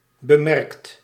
Ääntäminen
Ääntäminen Tuntematon aksentti: IPA: /bəˈmɛrkt/ Haettu sana löytyi näillä lähdekielillä: hollanti Käännöksiä ei löytynyt valitulle kohdekielelle.